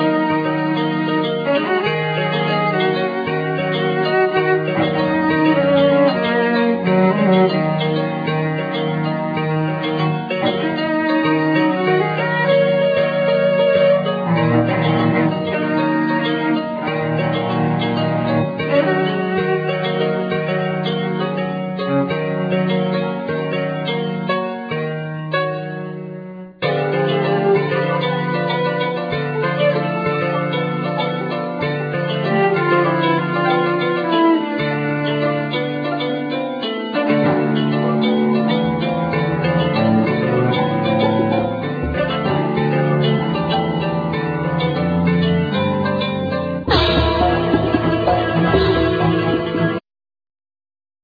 Bandneon
Trombone
Baryton saxophone
Voice
Contrabass
Gong
Programming,Sample
Tabla
Djembe
1st violin
Viola
Cello